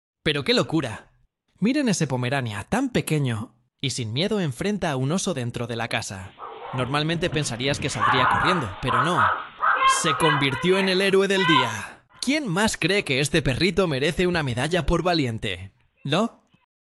perro pomerania ahuyenta un oso